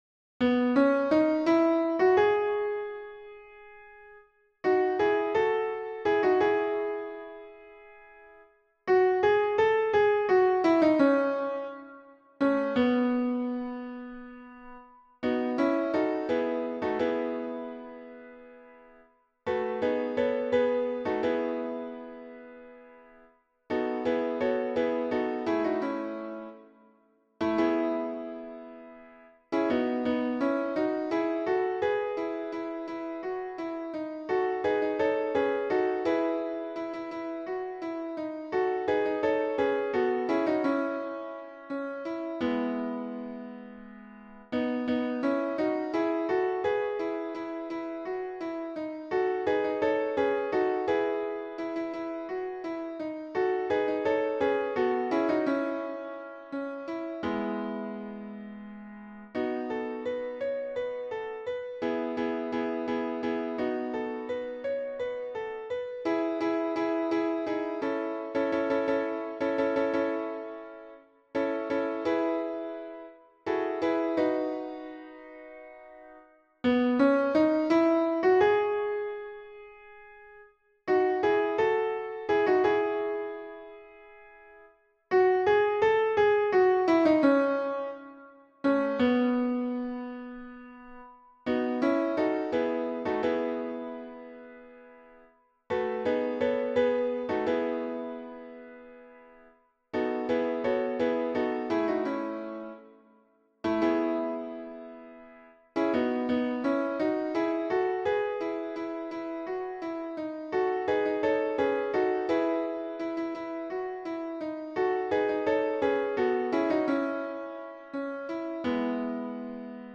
- Œuvre pour chœur à 4 voix mixtes (SATB)
version piano (4 voix)